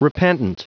Prononciation du mot repentant en anglais (fichier audio)
Prononciation du mot : repentant